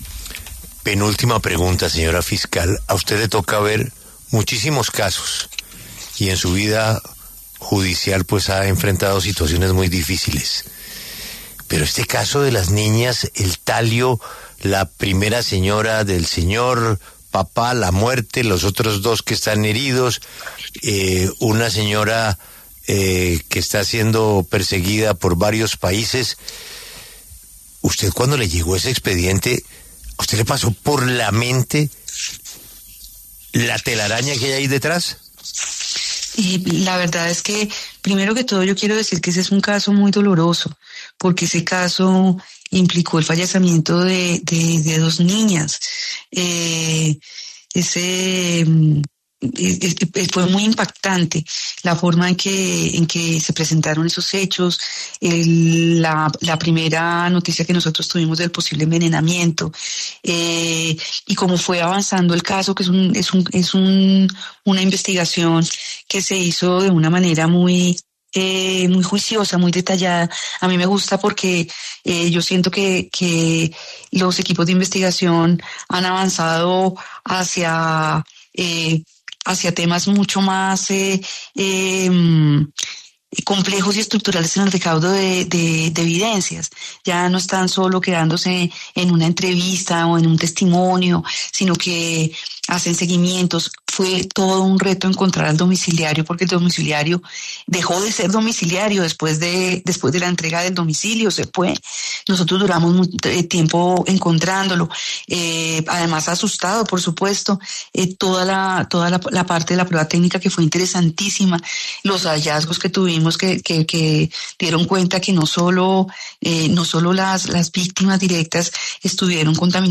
En esa línea, en exclusiva habló en La W, con Julio Sánchez Cristo, la fiscal general de la Nación, Luz Adriana Camargo, para explicar el tema.